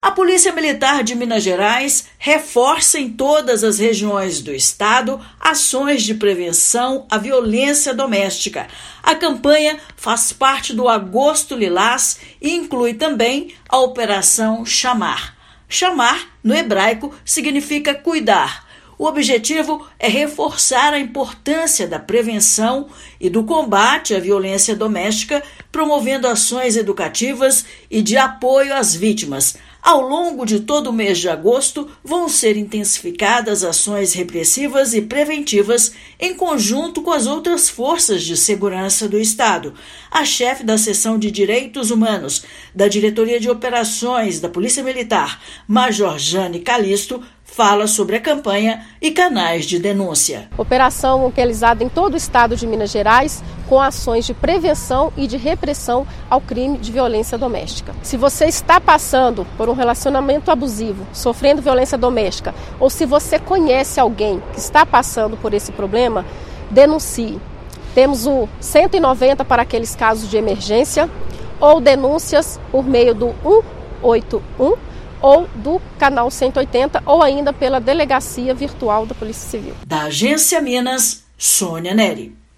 [RÁDIO] Polícia Militar reforça ações de prevenção à violência doméstica em Minas
Campanha Agosto Lilás é tema de operações educativas, repressivas e preventivas, também em conjunto com outras Forças de Segurança. Ouça matéria de rádio.